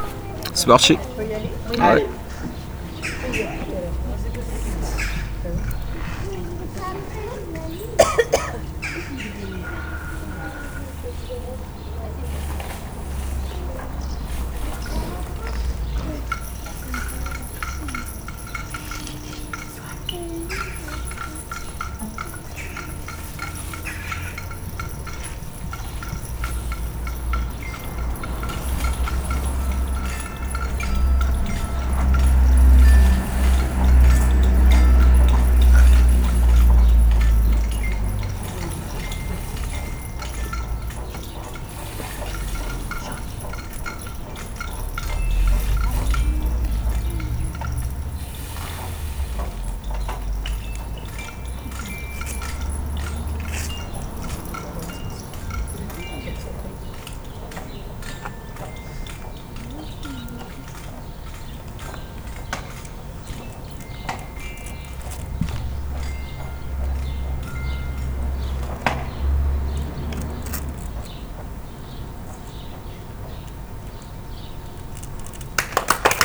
-les bâtons de pluie
-les crécelles
-les élastophones
-les xylophone
-les maracas
Ensuite nous avons expérimenté, manipulé, produits des sons en essayant chaque instrument.
Pour finir, chaque groupe a joué un petit concert avec les instruments.